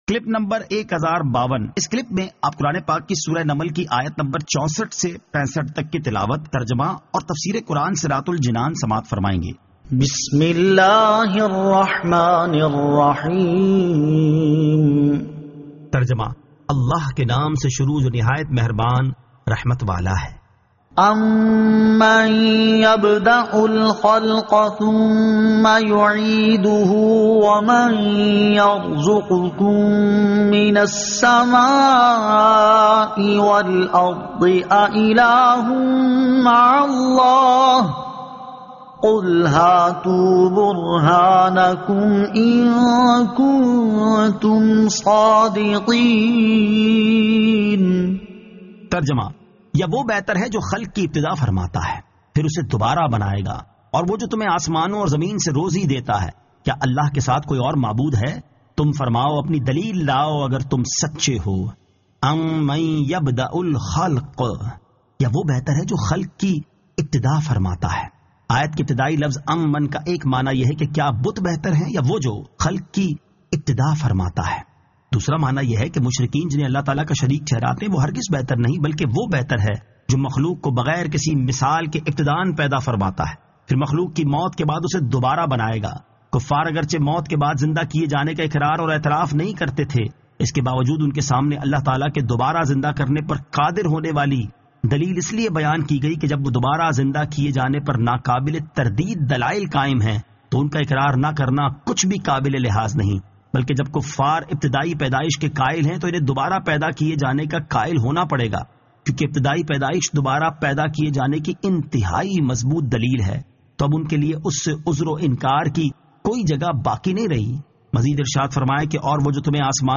Surah An-Naml 64 To 65 Tilawat , Tarjama , Tafseer